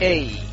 Applause